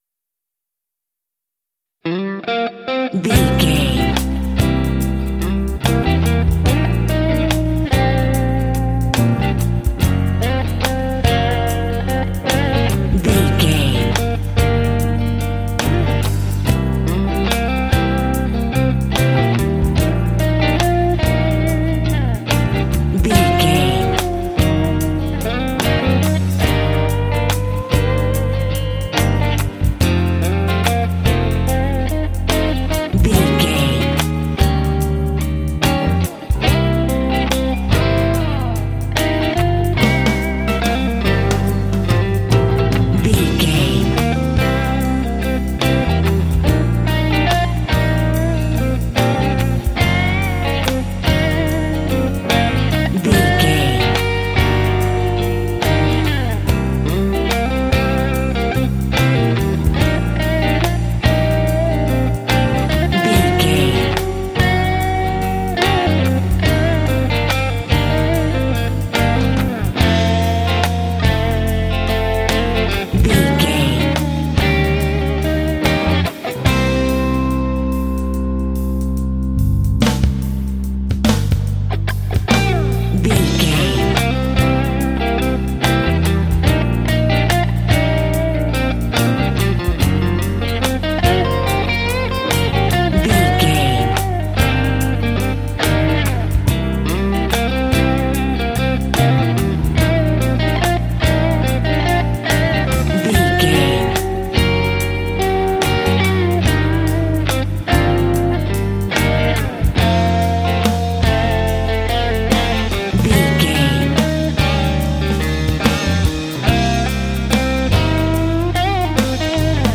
Mixolydian
happy
bouncy
groovy
electric guitar
bass guitar
drums
piano
americana